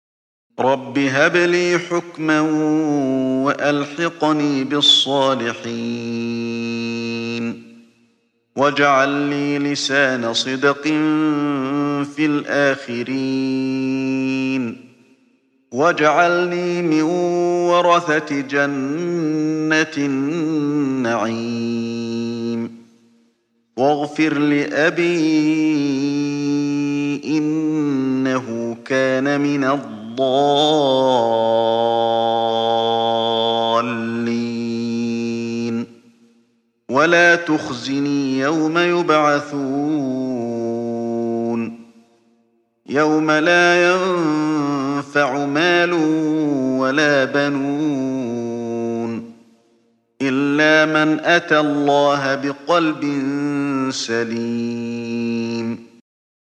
Чтение аятов 83-89 суры «аш-Шу’ара» шейхом ’Али бин ’Абд ар-Рахманом аль-Хузейфи, да хранит его Аллах.